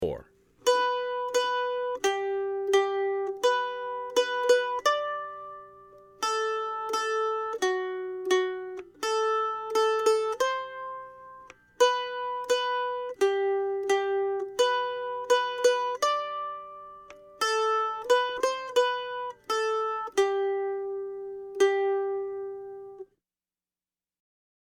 Voicing: Mandolin M